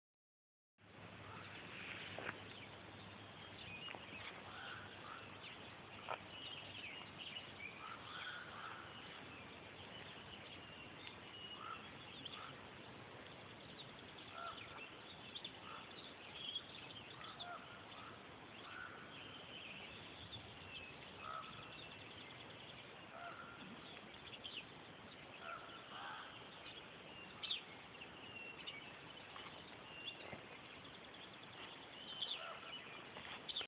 Birds.mp3